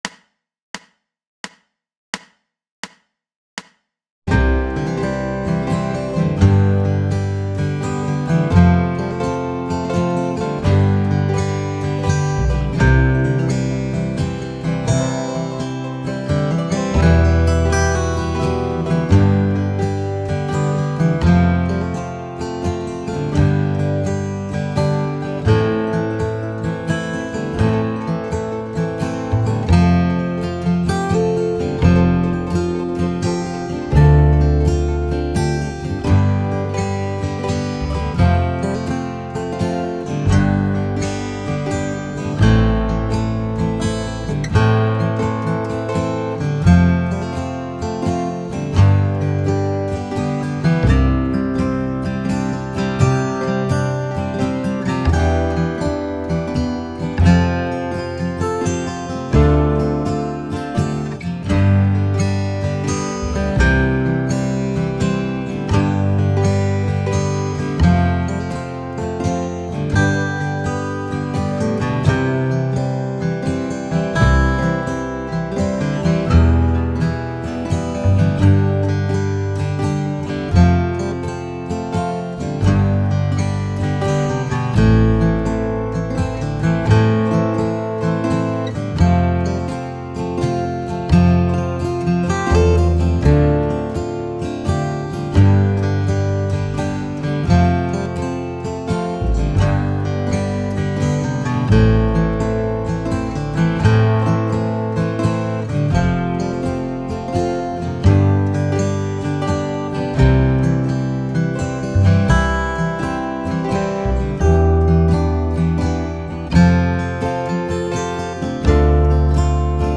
And mute the drums.